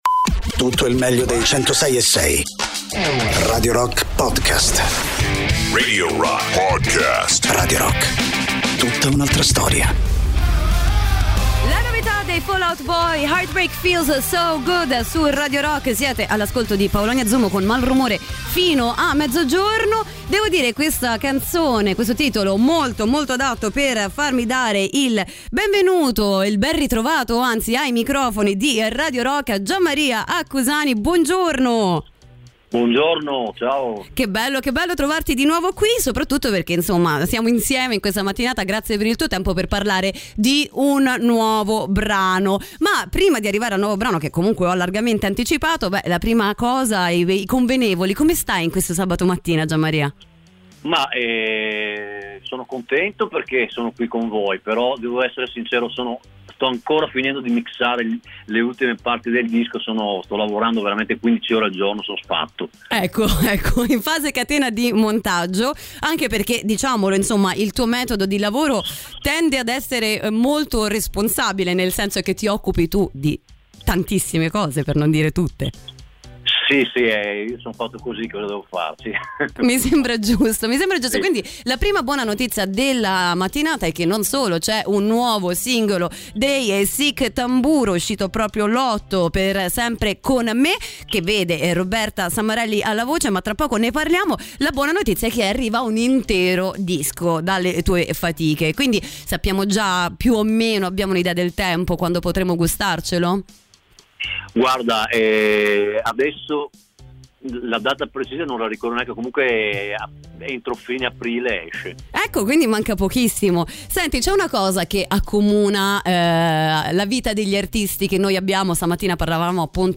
Interviste: Gian Maria Accusani (11-03-23)